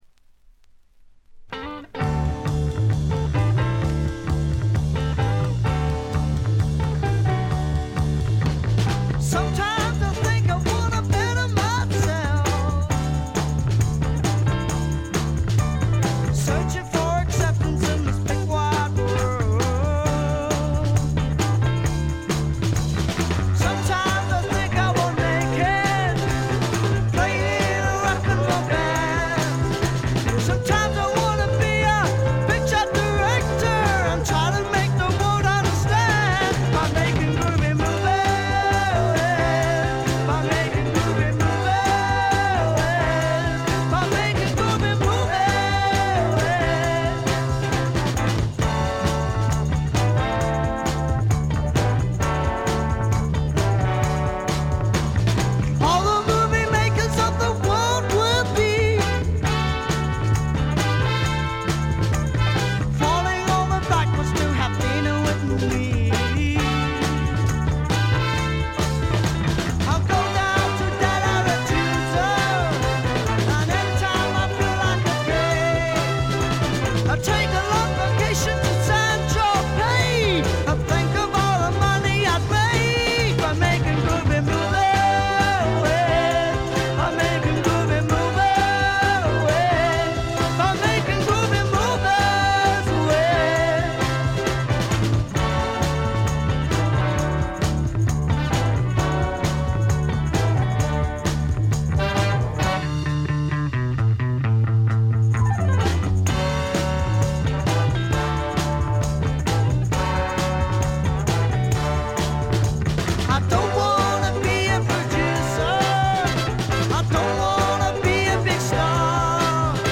チリプチがちらほら。
試聴曲は現品からの取り込み音源です。